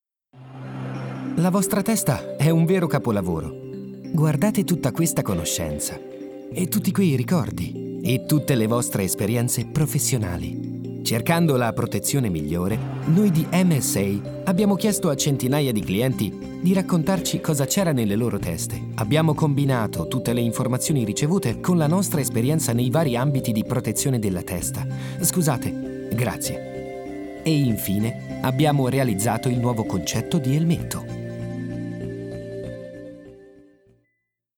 Ho una voce calda e giovane, utilizzabile in ogni tipo di progetto.
Sprechprobe: eLearning (Muttersprache):